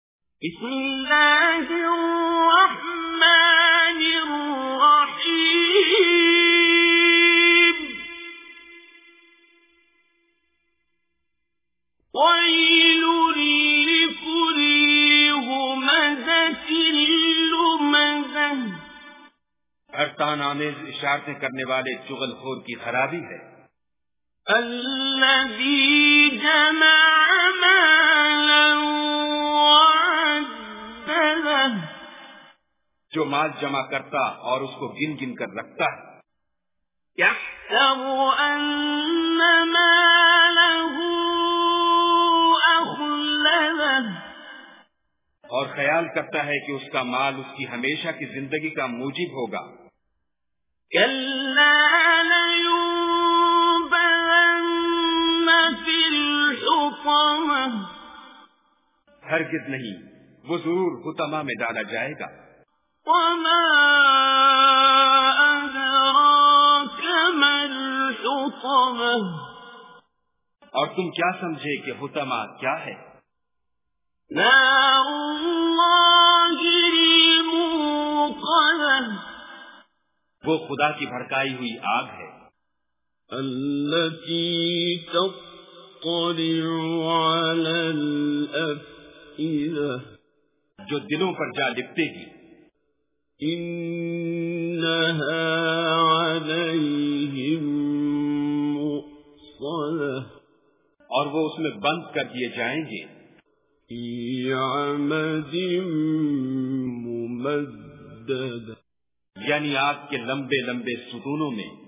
Surah Al-Humazah Recitation with Urdu Translation
Surah Al-Humazah is 104th chapter of Holy Quran. Listen online and download mp3 tilawat / recitation of Surah Al-Humazah in the voice of Qari Abdul Basit As Samad.